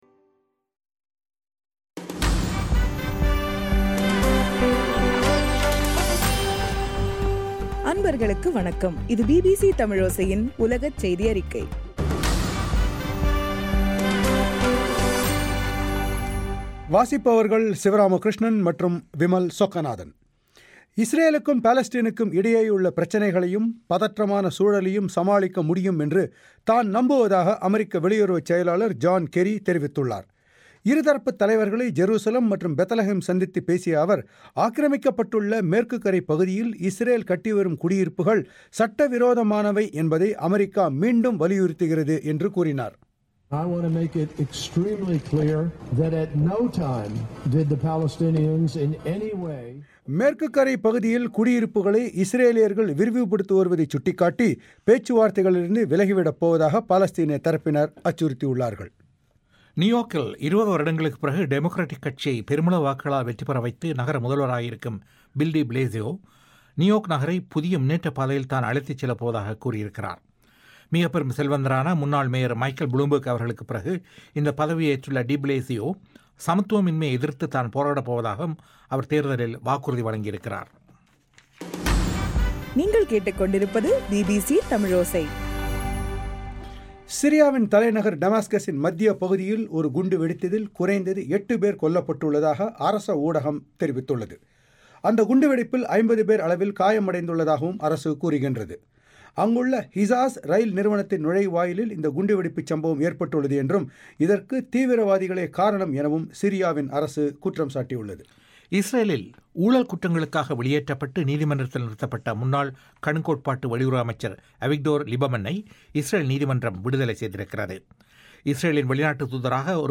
நவம்பர் 6 தமிழோசை உலகச் செய்திகள்